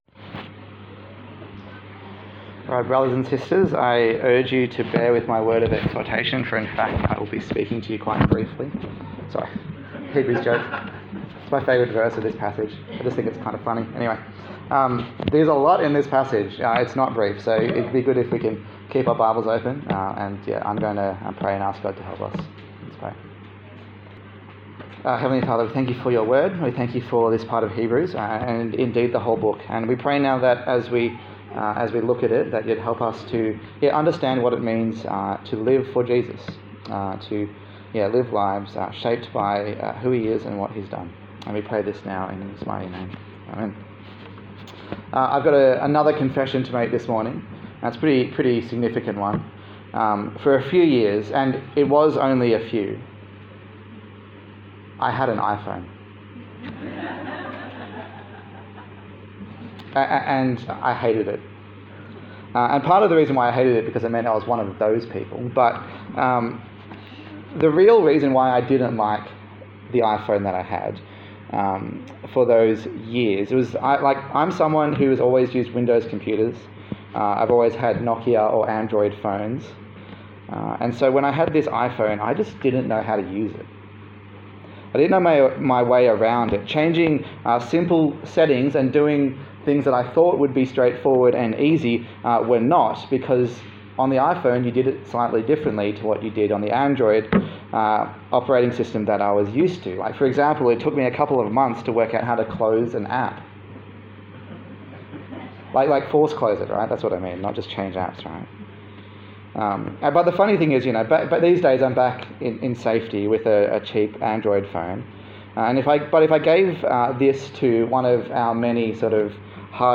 A sermon in the series on the letter to the Hebrews
Service Type: Sunday Morning